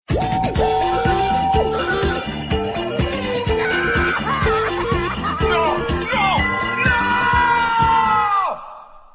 All tracks encoded in mp3 audio lo-fi quality.
christmas1 oh no! sweeper